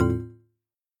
Bonk Click w/deny feel
button click game hi-tech interface menu option press sound effect free sound royalty free Sound Effects